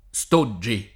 — voce fam. tosc. per «moine» e sim., usata nel ’600 e ’700 — prob. allòtropo pop. di studi (lat. studium con -u- breve); quindi verosimile -o- chiuso